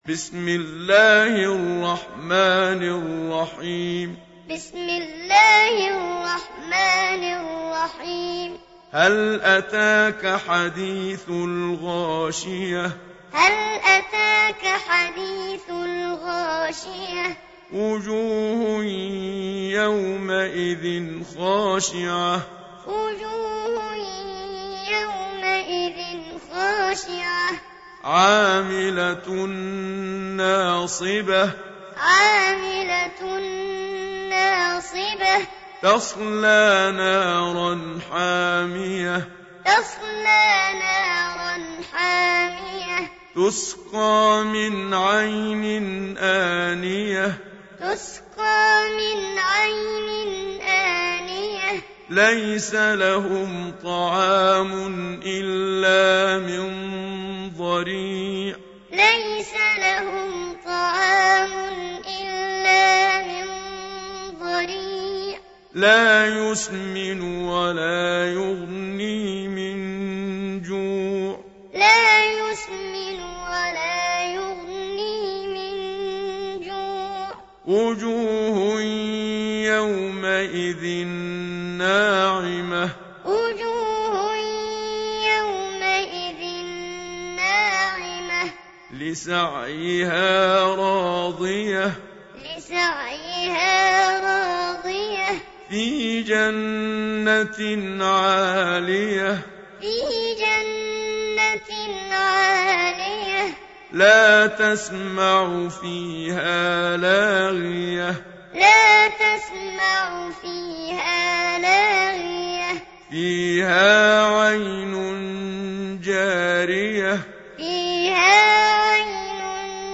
Surah Sequence تتابع السورة Download Surah حمّل السورة Reciting Muallamah Tutorial Audio for 88. Surah Al-Gh�shiyah سورة الغاشية N.B *Surah Includes Al-Basmalah Reciters Sequents تتابع التلاوات Reciters Repeats تكرار التلاوات